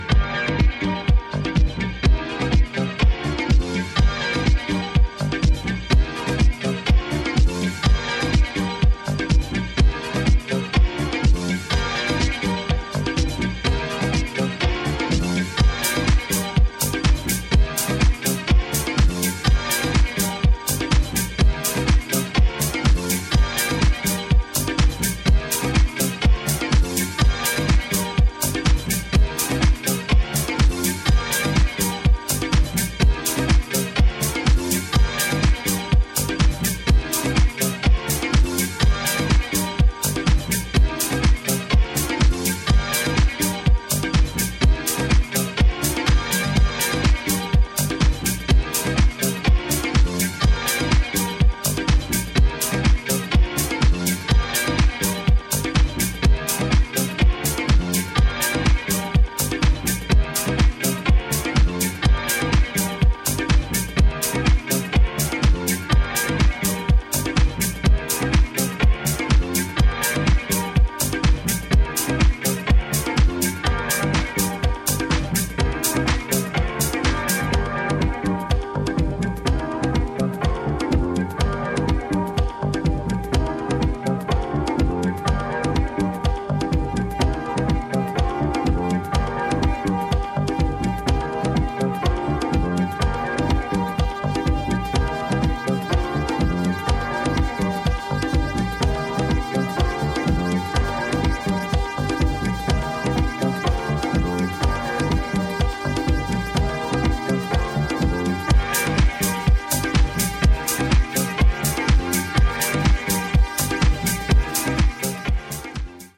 Instrumental Vinyl Only version